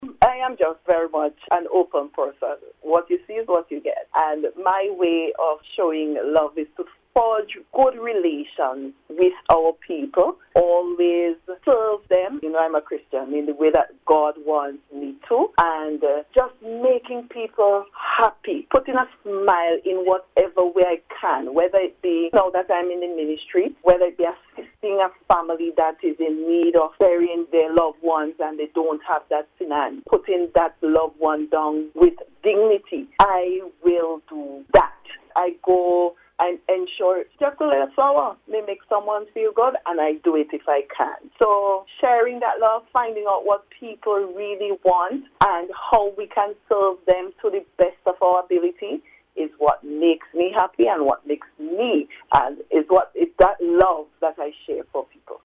In a recent interview, Minister John reflected that love is a daily practice; one that guides her ministry’s commitment to the most vulnerable citizens in Saint Vincent and the Grenadines.